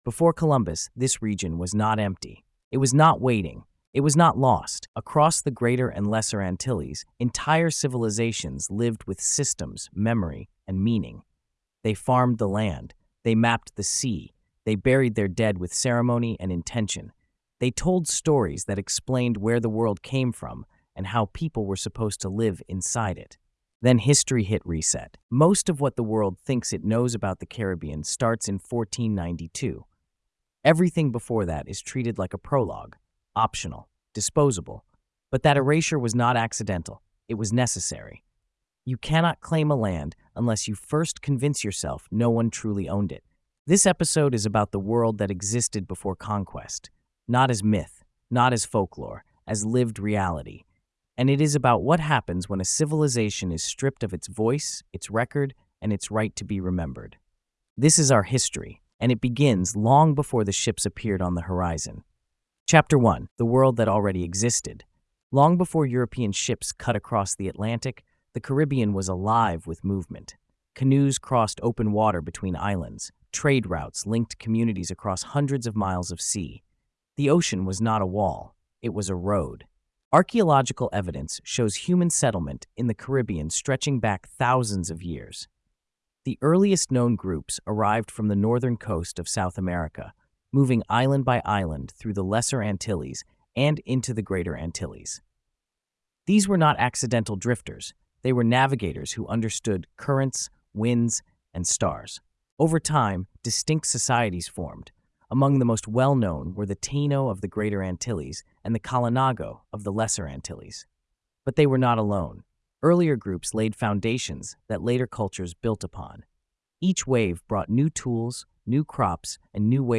Told with a gritty documentary voice, the episode explores how these civilizations lived, how their knowledge was passed down without written scripts, and how erasure began even before violence through distortion, renaming, and silencing. It traces how loss was engineered and how fragments of Indigenous survival still shape Caribbean identity today.